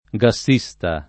vai all'elenco alfabetico delle voci ingrandisci il carattere 100% rimpicciolisci il carattere stampa invia tramite posta elettronica codividi su Facebook gassista [ g a SS&S ta ] o gasista [ g a @&S ta ] s. m. e f.; pl. m. -sti